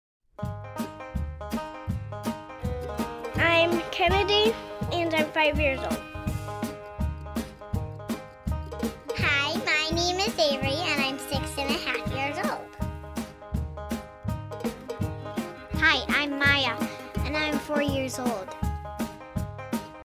enthusiastic singing will make you grin!